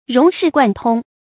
融釋貫通 注音： ㄖㄨㄙˊ ㄕㄧˋ ㄍㄨㄢˋ ㄊㄨㄙ 讀音讀法： 意思解釋： 把各方面的知識和道理融化匯合，得到全面透徹的理解。